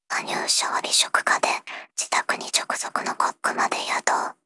voicevox-voice-corpus
voicevox-voice-corpus / ROHAN-corpus /ナースロボ＿タイプＴ_内緒話 /ROHAN4600_0021.wav